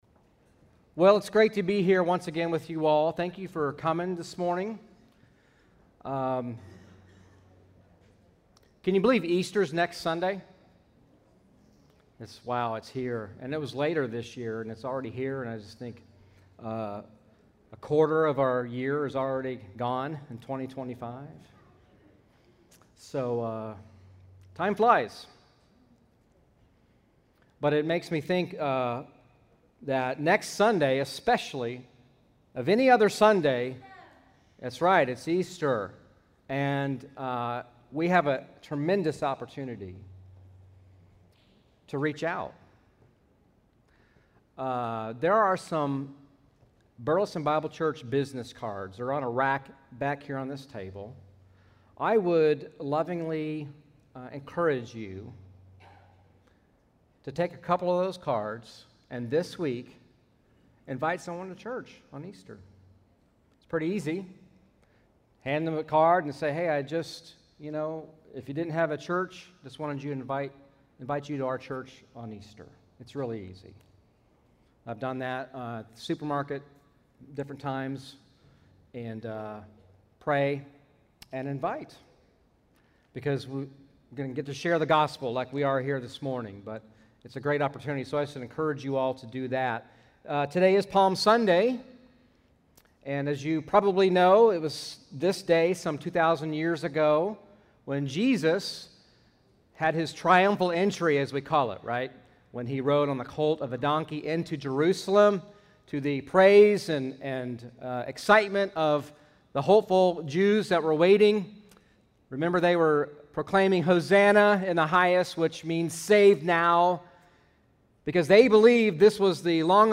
Why was there no other way to redeem us and bring us to God except through the death of Christ? This Sunday, on Palm Sunday, we will explore the answer to this question, which will inspire us to humbly proclaim the love and goodness of God.